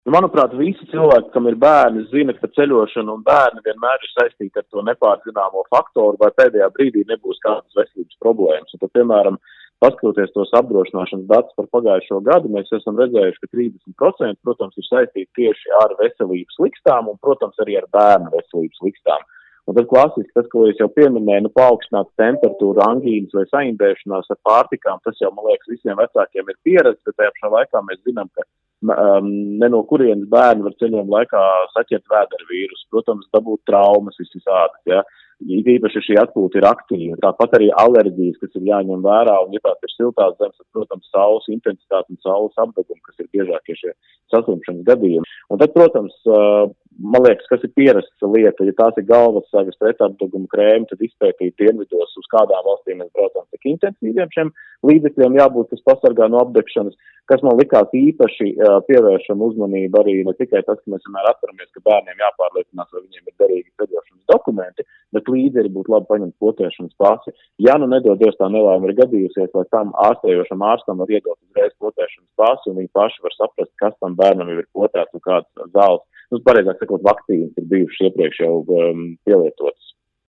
RADIO SKONTO Ziņās par to, kam jāpievērš uzmanība ceļojot ar bērnu